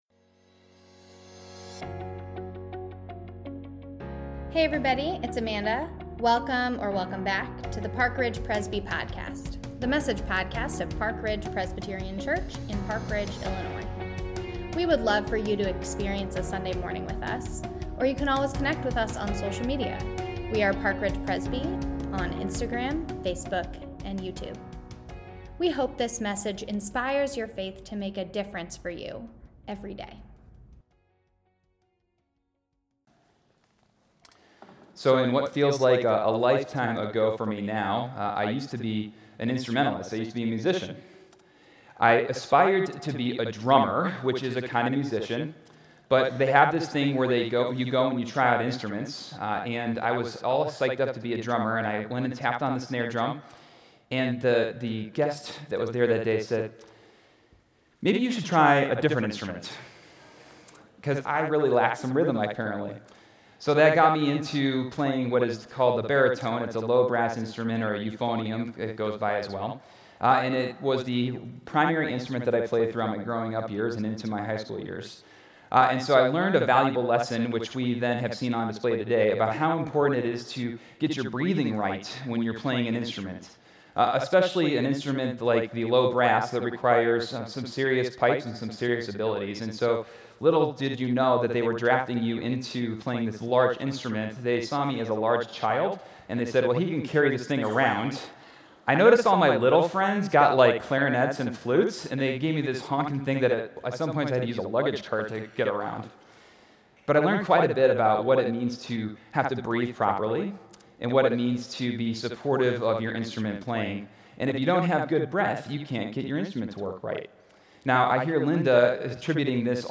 This message is from Music Sunday 2020.